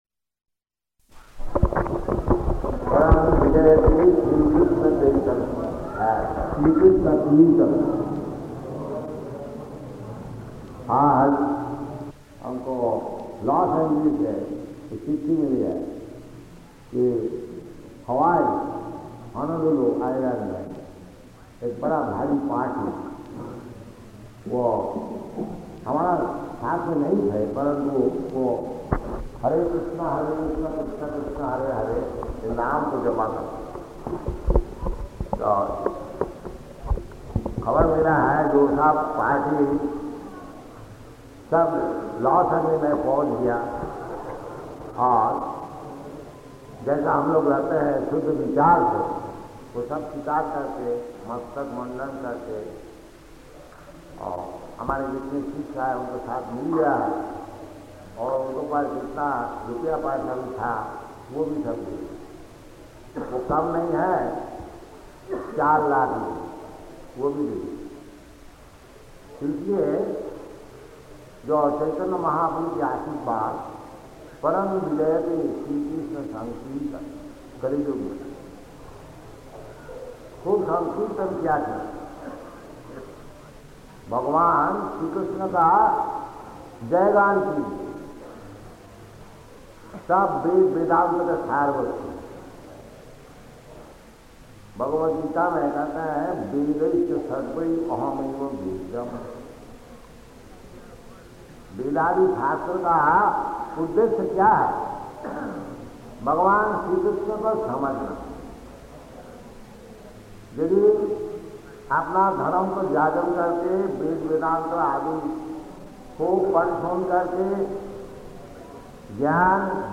Lecture in Hindi
Type: Lectures and Addresses
Location: Indore